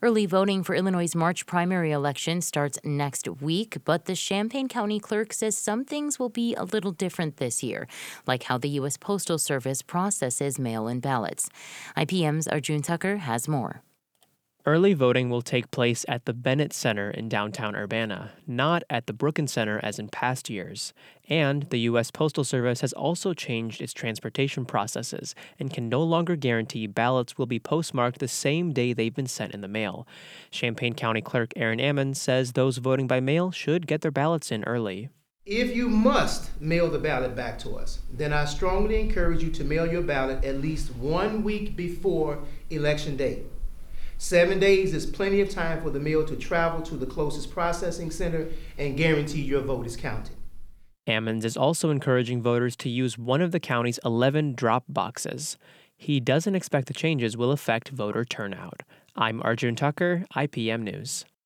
At a press conference Tuesday morning, Ammons said his office has been in touch with the U.S. Postal Service (USPS) regarding changes to the agency’s mail transportation process affecting postmarked ballots.
Aaron-Ammons-Postmarks-Press-Conference.mp3